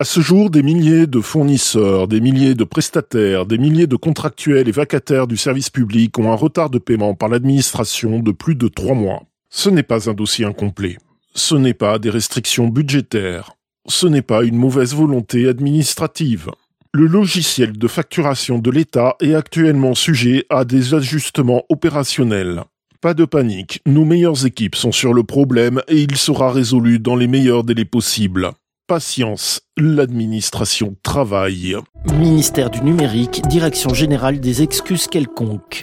Chroniques › Fausse publicité
[Signature musicale de la République Française, significativement modifiée] Ministère du Numérique